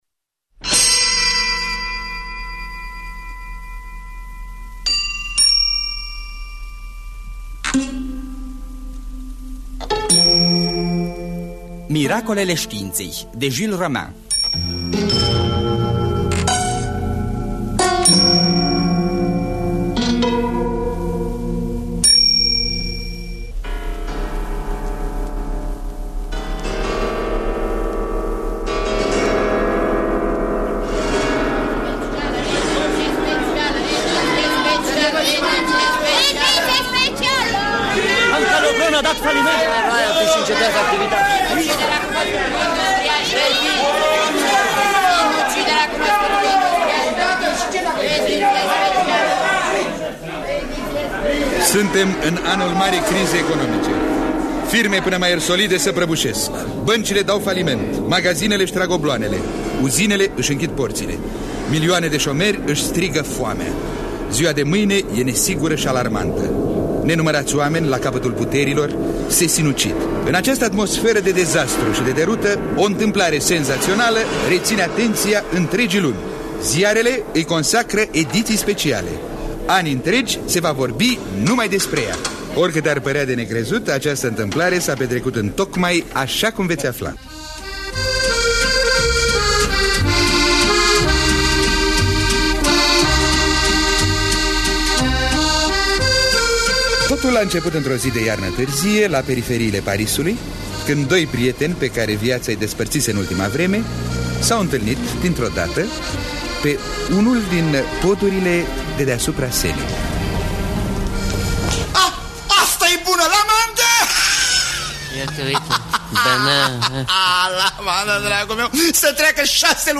Miracolele științei de Jules Romains – Teatru Radiofonic Online